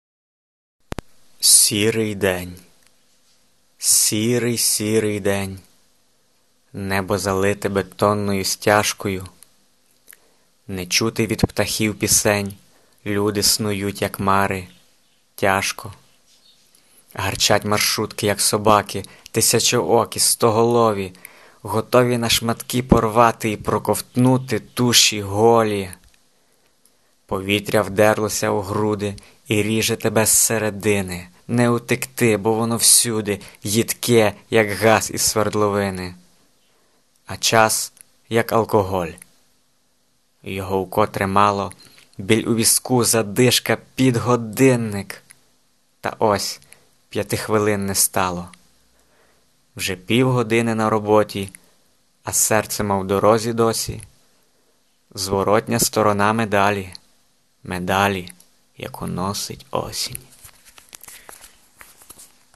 Похмуро...Чудово читаєш frown